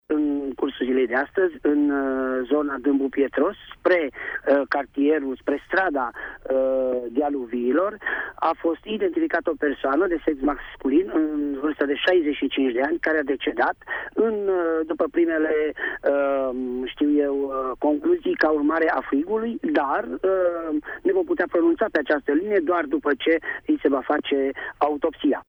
O persoană a fost găsită decedată astăzi într-o casă de vacanță din cartierul Dâmbu Pietros. Este vorba despre un bărbat în vârstă de 65 de ani, a declarat în emisiunea „Sens Unic” de la RTM, șeful Poliției Locale Tîrgu Mureș, Valentin Bretfelean: